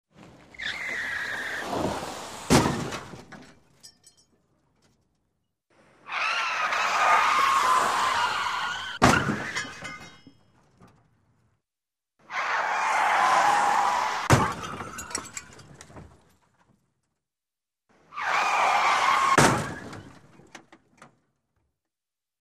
Звуки аварий автомобилей
На этой странице собраны реалистичные звуки автомобильных аварий: резкое торможение, столкновения, переворачивающиеся машины и другие тревожные моменты.
1. Автомобильная авария с заносом n2. ДТП с заносом машины n3. Занос автомобиля и авария n4. Аварийный занос транспортного средства